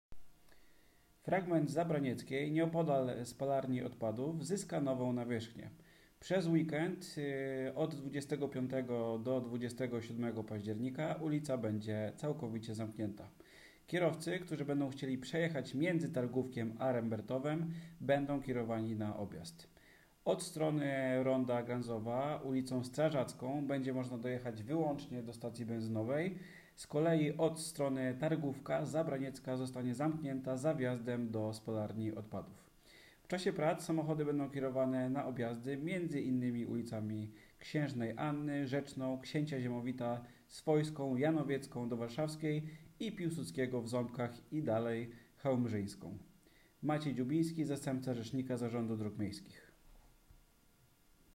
Zabraniecka-frezowanie-1.m4a